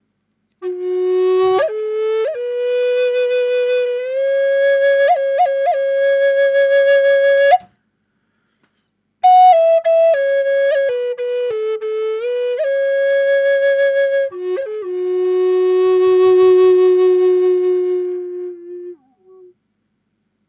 F#4 sävellajin Natiivihuilu
Natiiviamerikkalaishuilu F#4 sävellajissa. Matalahko ja lempeä sointitaajuus. Viritetty pentatoniseen molli sävelasteikkoon.
• Vire: Pentatoninen molli (440 hz)
Ääninäyte ilman efektejä (dry):
Fis4_440_pentatoninenmolli_DRY.mp3